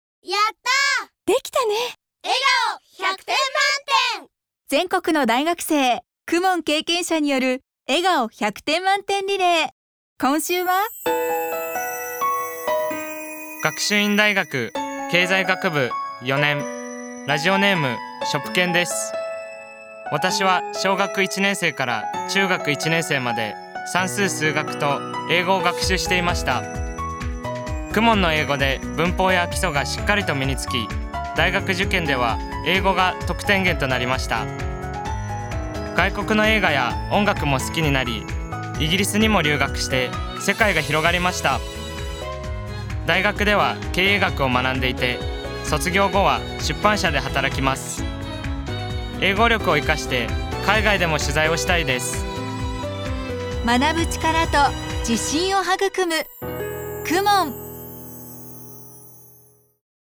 全国の大学生の声